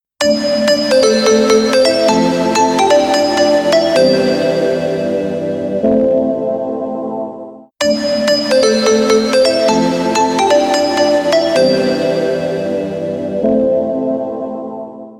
• Качество: 320, Stereo
без слов